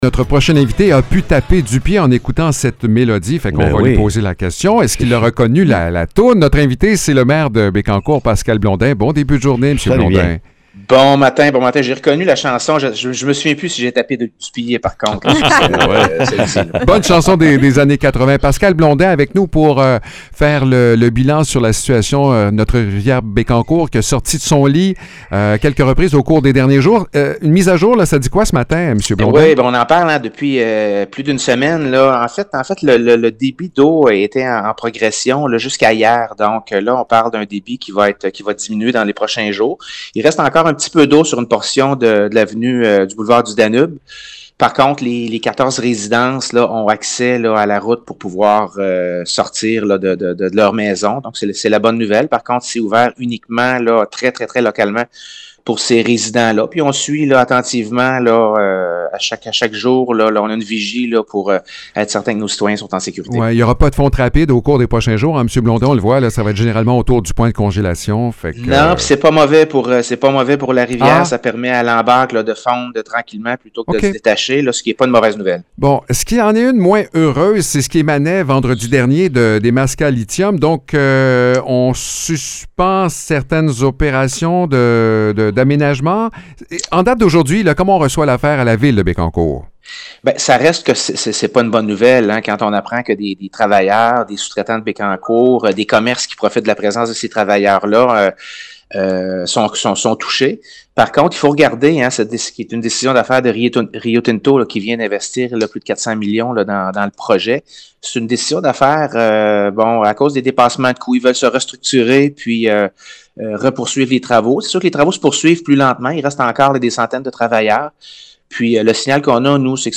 Le maire de Bécancour, Pascal Blondin, fait le point sur la gestion de la crue des eaux. Après une hausse du débit observée dans les derniers jours, la situation semble se stabiliser. Malgré certaines inquiétudes entourant Nemaska Lithium, le maire se veut rassurant.